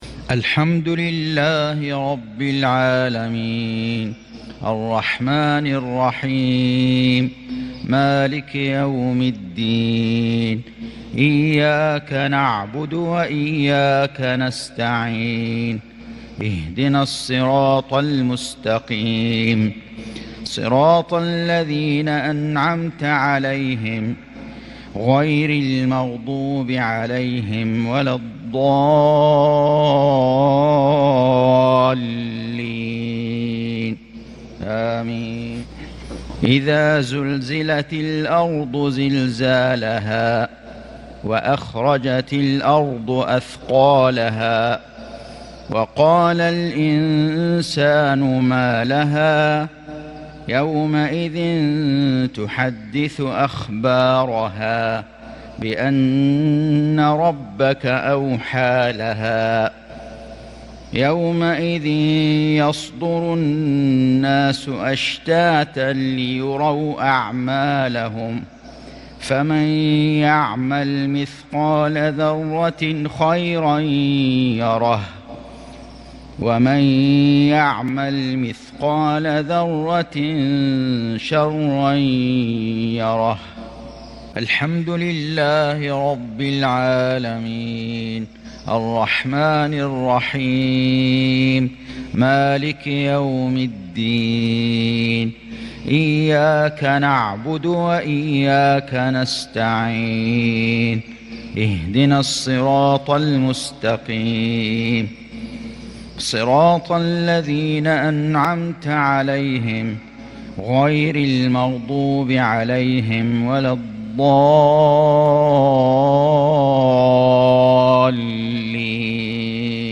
تلاوة من سورتي الزلزلة والقارعة مغرب الأربعاء ١٩ رجب ١٤٤٢هـ | maghrib prayer from surat zalzalah & qarea'a 3-3-2021 > 1442 🕋 > الفروض - تلاوات الحرمين